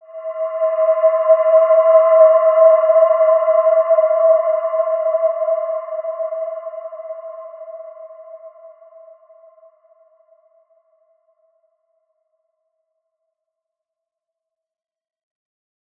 Wide-Dimension-E4-mf.wav